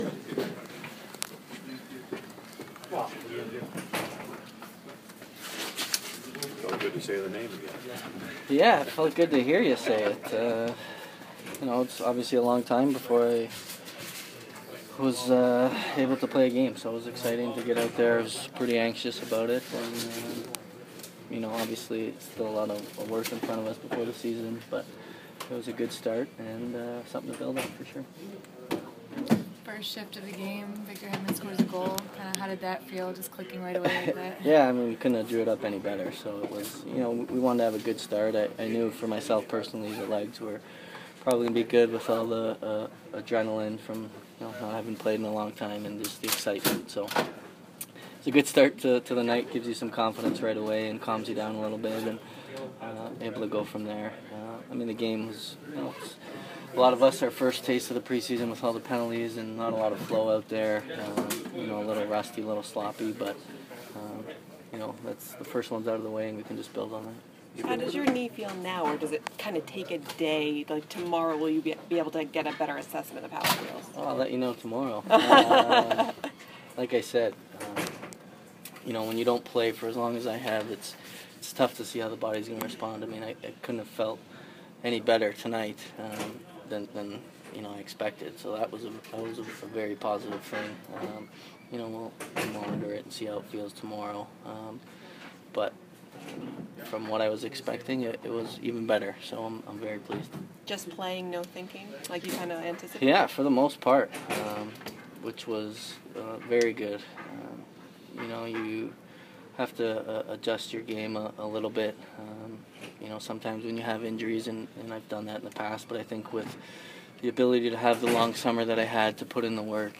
Steven Stamkos Post Game Interview 9/22
Captain Steven Stamkos addresses the media after the second preseason game against Nashville.